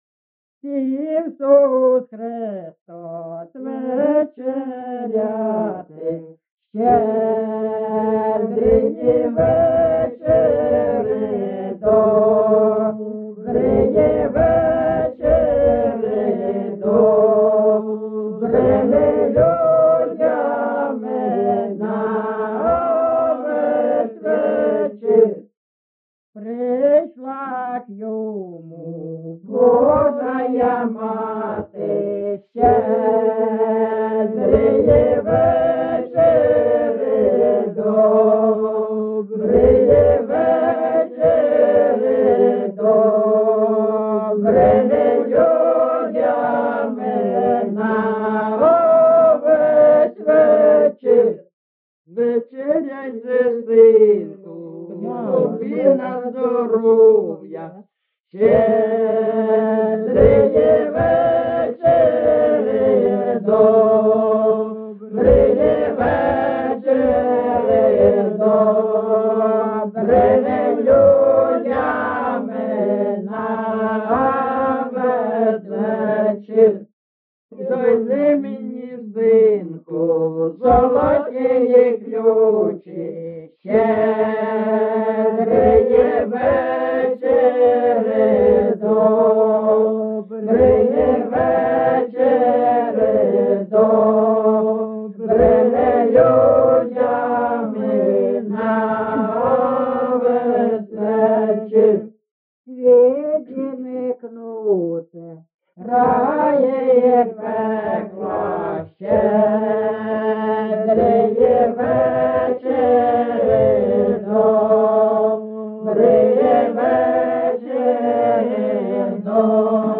ЖанрЩедрівки
Місце записус. Лиман, Зміївський (Чугуївський) район, Харківська обл., Україна, Слобожанщина